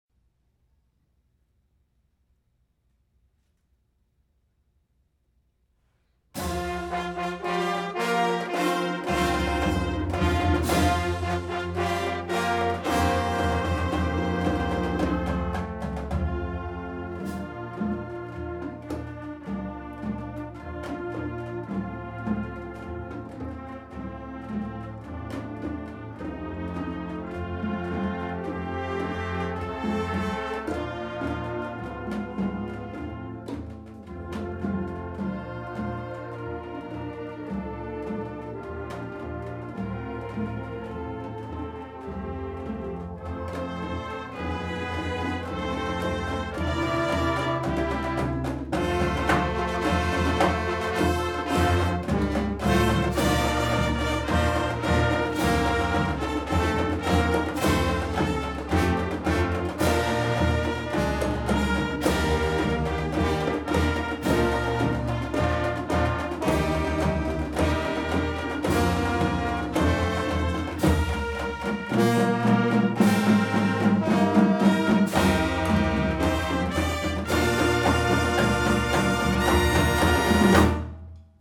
The Marching Band performed at the 55th Presidential Inaugural Parade in Washington, DC.